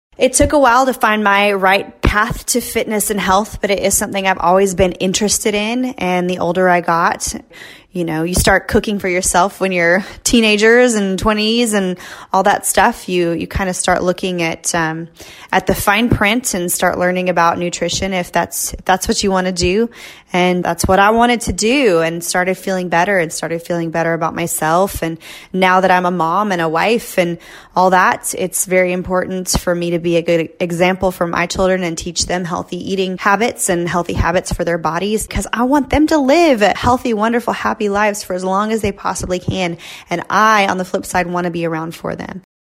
Carrie Underwood talks about her path to fitness.